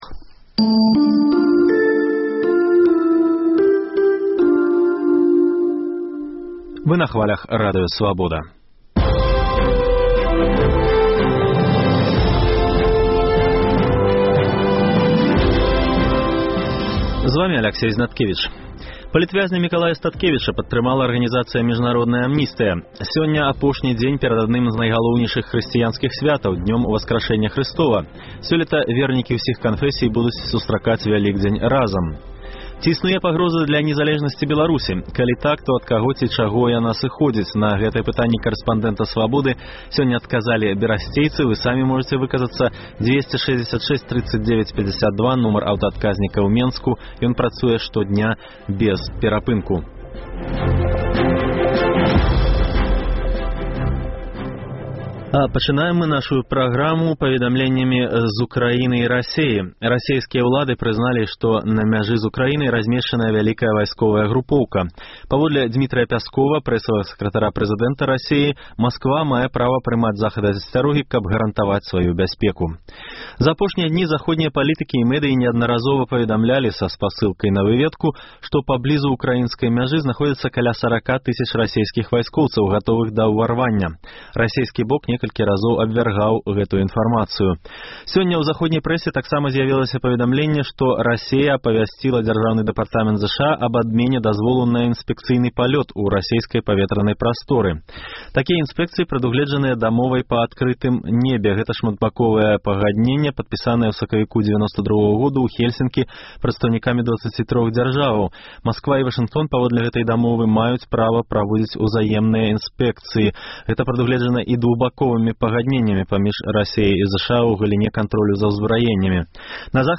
На гэтыя пытаньні карэспандэнта «Свабоды» адказвалі сёньня берасьцейцы.